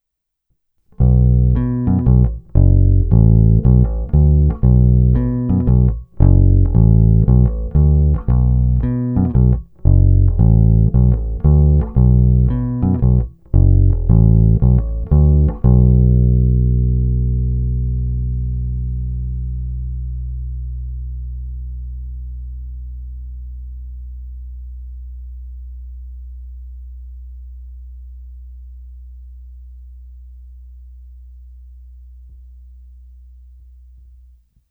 Zvuk je tučný, na jeho pozadí i přes tupé struny slyším zvonivé vrčení typické právě pro modely 1957-1959.
Není-li řečeno jinak, následující nahrávky jsou provedeny rovnou do zvukové karty a kromě normalizace ponechány bez úprav. Tónová clona vždy plně otevřená.
Hra mezi snímačem a krkem